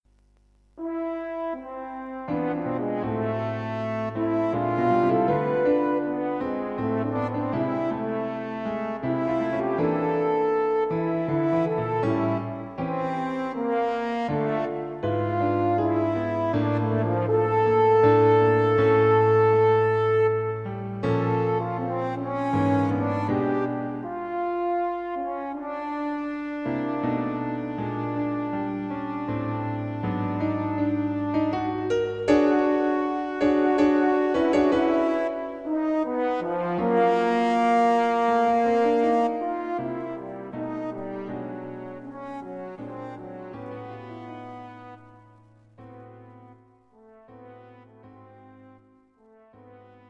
It's a good night-time piece.